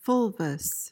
PRONUNCIATION: (FUHL-vuhs) MEANING: adjective: Tawny; brownish-yellow or orange.